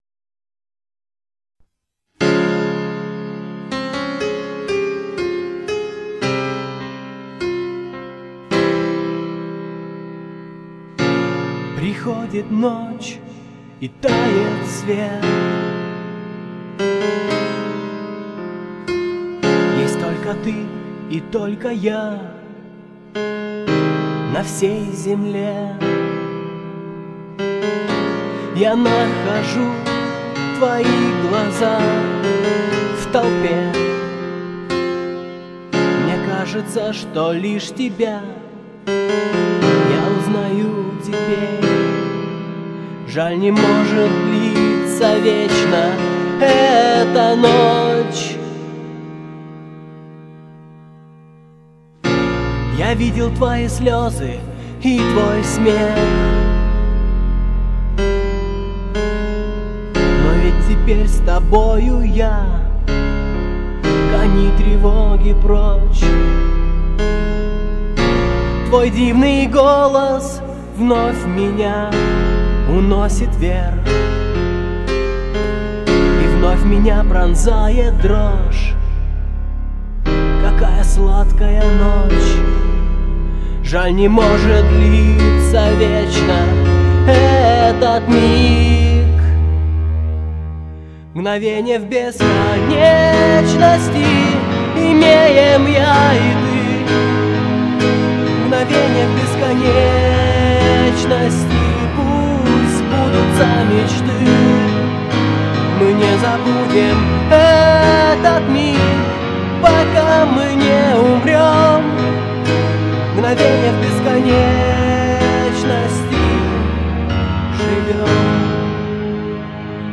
Клавиши, вокал